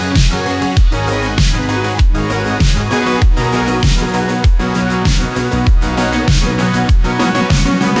"prompt": " Driving 80s-style electronic with lush synth textures, arpeggiated basslines, retro guitar licks, and dramatic gated drums.